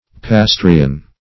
Search Result for " pasteurian" : Wordnet 3.0 ADJECTIVE (1) 1. of or relating to Louis Pasteur or his experiments ; The Collaborative International Dictionary of English v.0.48: Pasteurian \Pas*teur"i*an\, prop. a. Of or pertaining to Louis Pasteur.
pasteurian.mp3